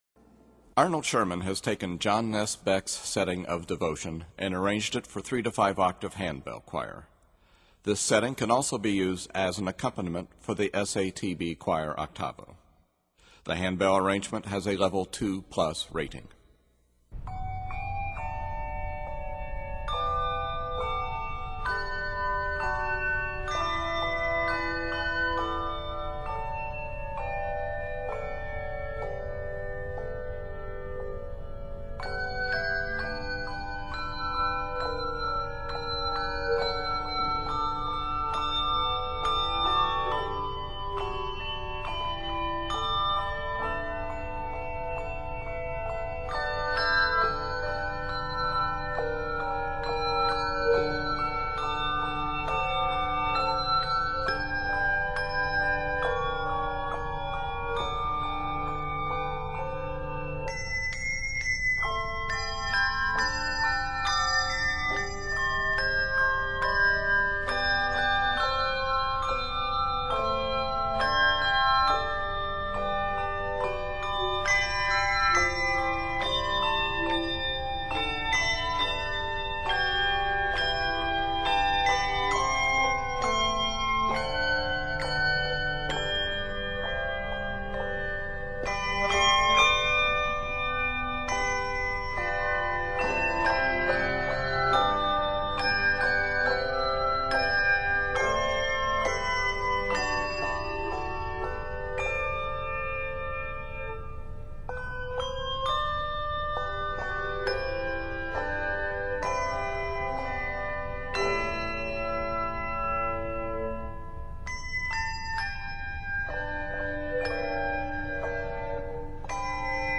handbell accompaniment